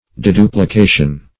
Search Result for " deduplication" : The Collaborative International Dictionary of English v.0.48: Deduplication \De*du`pli*ca"tion\, n. [Pref. de- + duplication.]